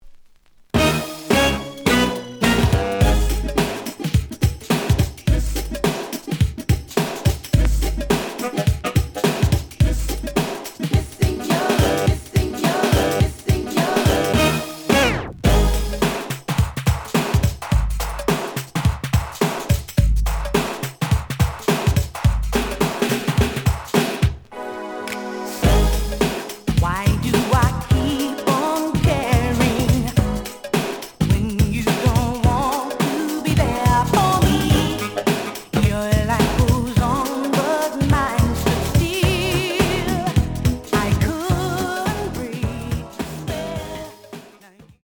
試聴は実際のレコードから録音しています。
●Genre: Hip Hop / R&B